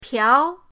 piao2.mp3